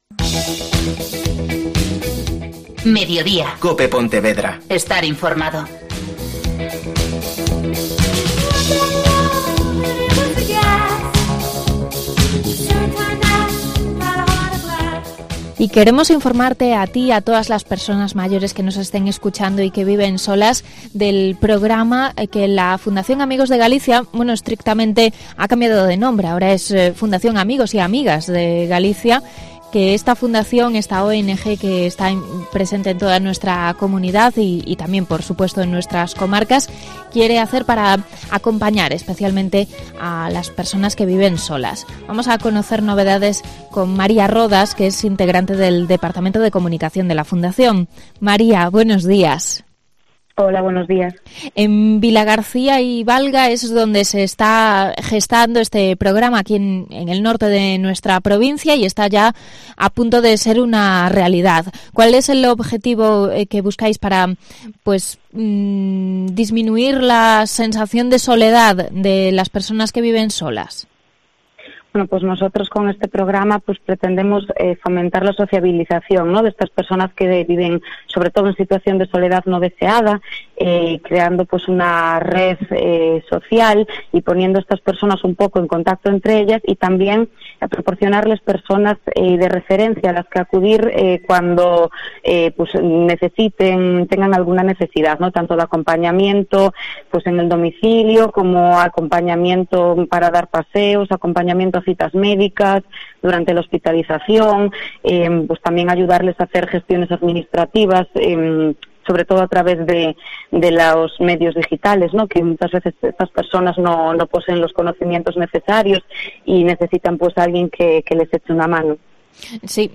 Entrevista sobre la atención a mayores que viven solos